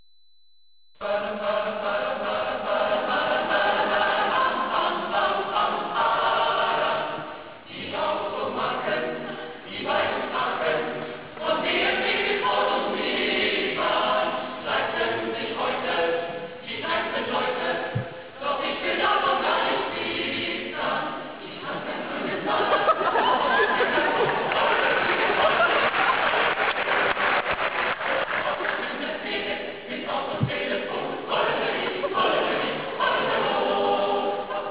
Entschuldigt die Qualität.
Singen!